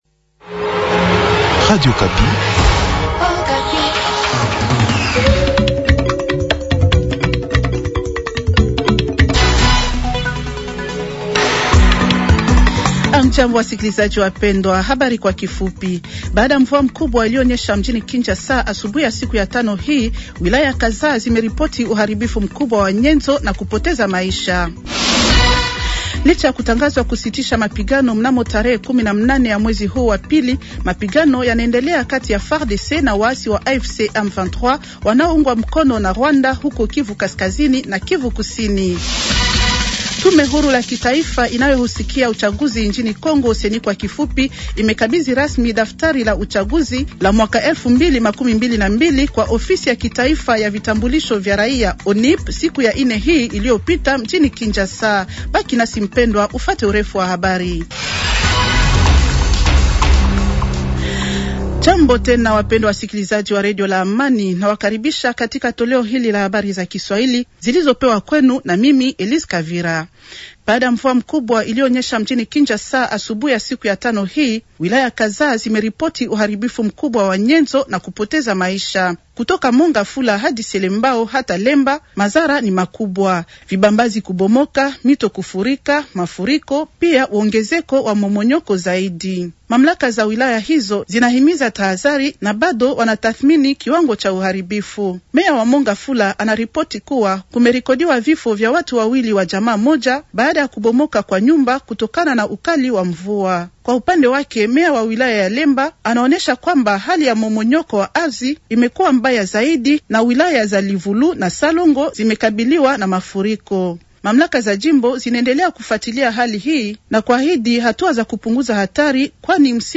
Journal Swahili du Vendredi 200226